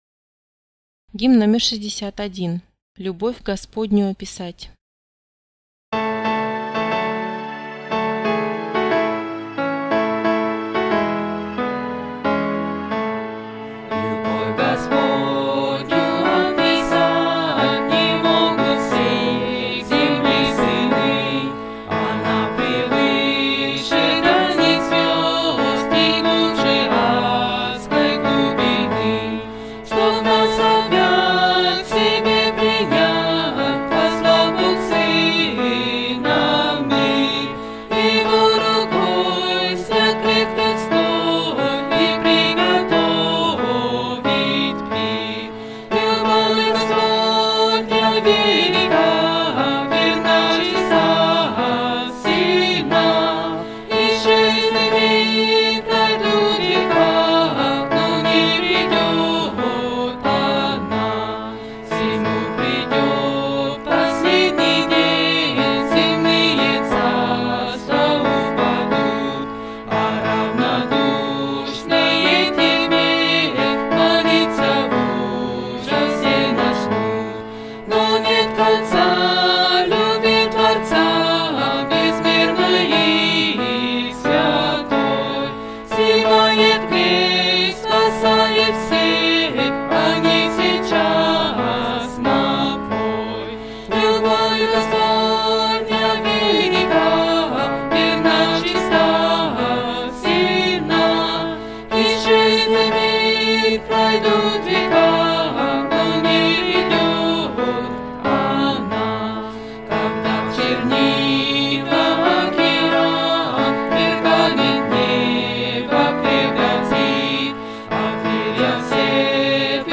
Тональность Ми-бемоль мажор